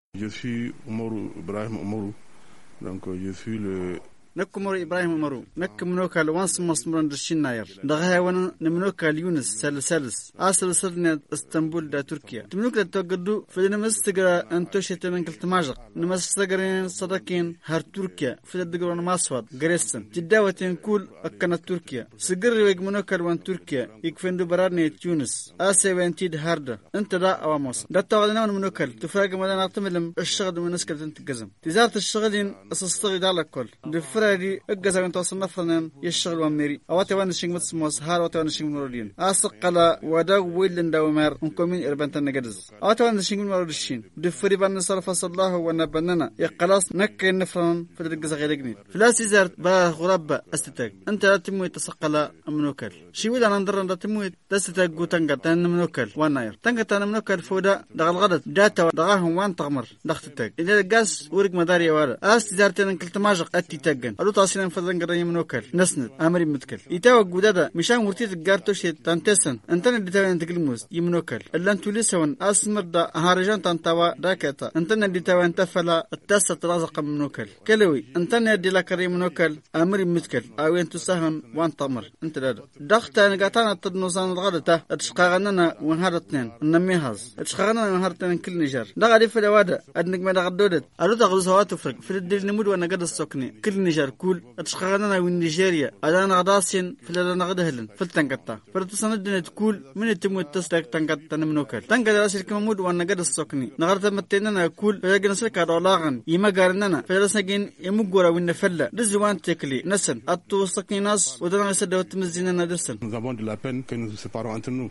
Vous le saurez dans l’entretien qu’il a accordé à notre envoyé spécial de retour d’Agadez .